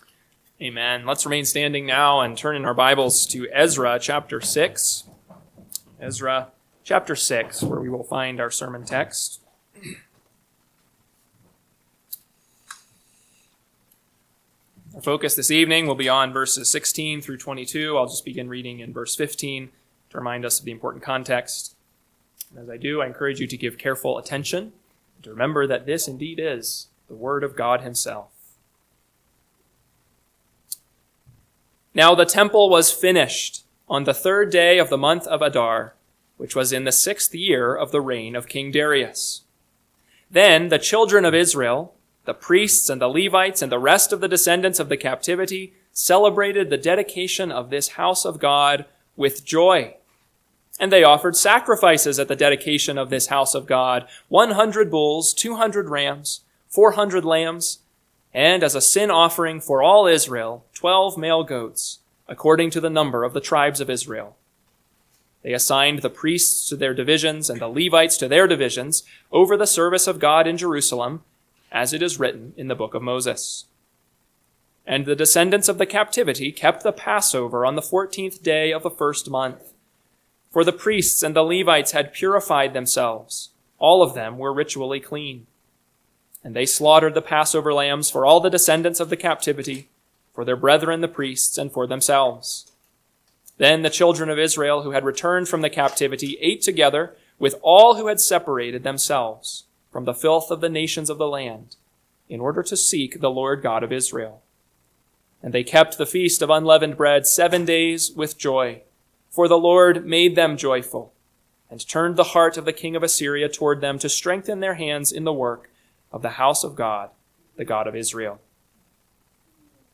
PM Sermon – 4/20/2025 – Ezra 6:16-22 – Northwoods Sermons